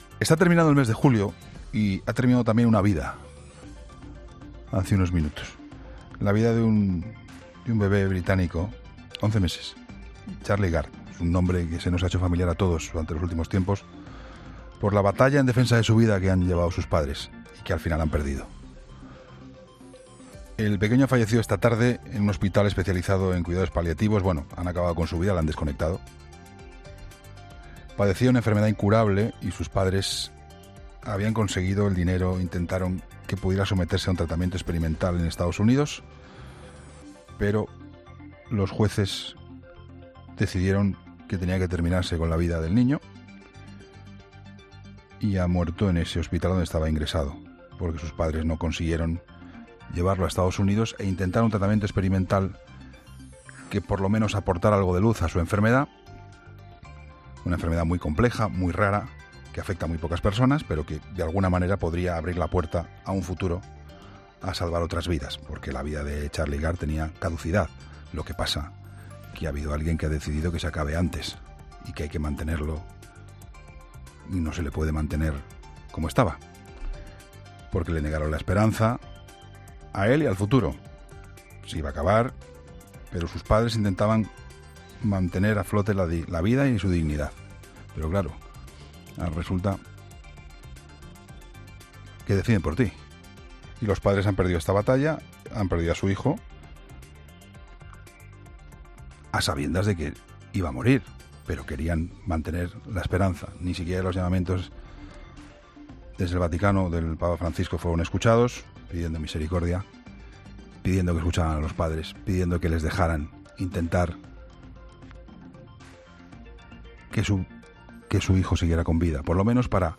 El análisis de Juan Pablo Colmenarejo tras el fallecimiento de Charlie Gard, bebé británico que ha sido desconectado de las máquinas que lo mantenían con vida en contra del deseo de sus padres